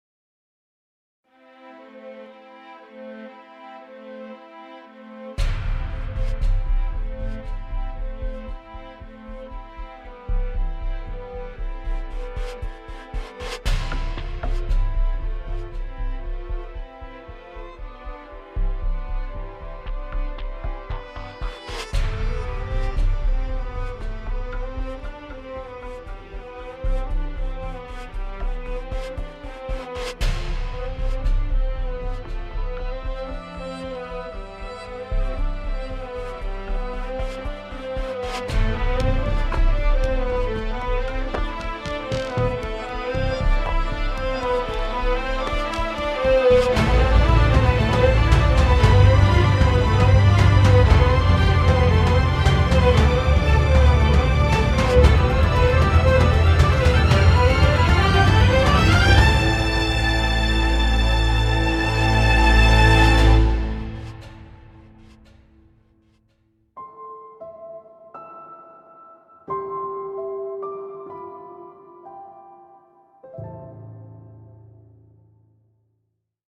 tema dizi müziği, duygusal hüzünlü gerilim fon müzik.